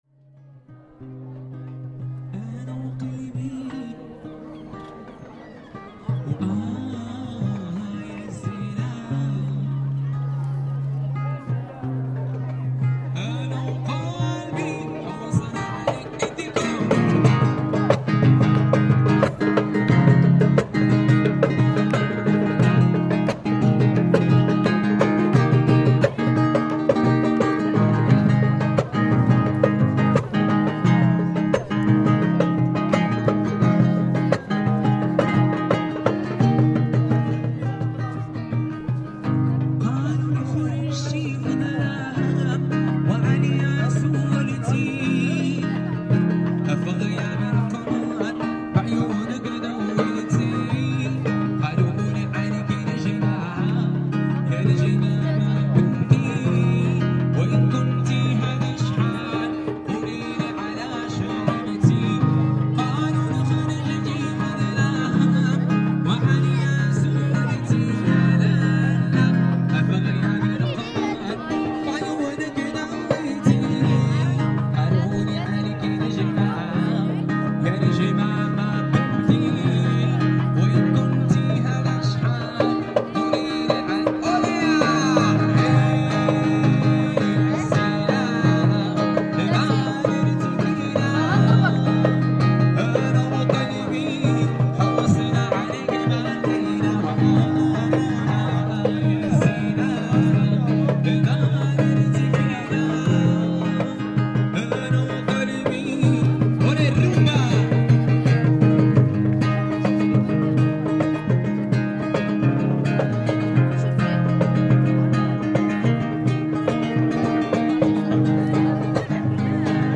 Place Moulay is one of the busiest spots in the otherwise pretty tranquil city of Essaouira - a prime spot for buskers to show off their skills performing the famous local music form gnaoua.